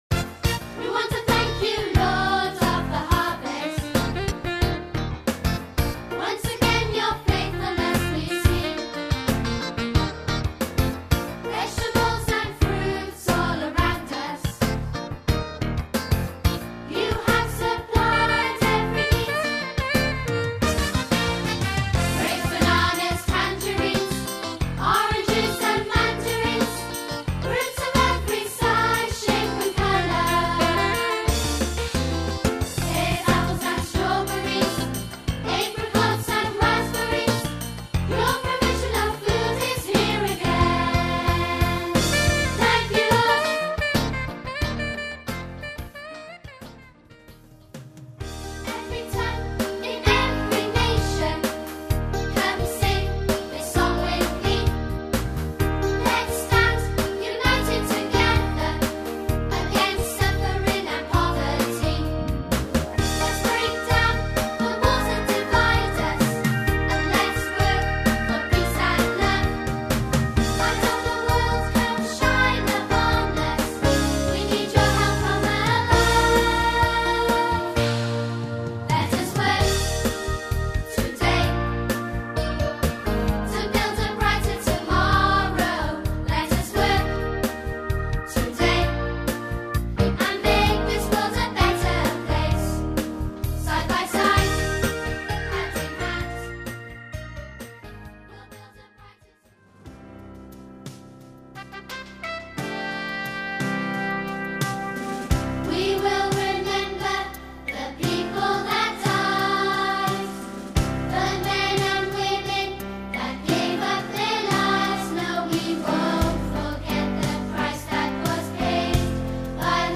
A collection of 14 new assembly songs.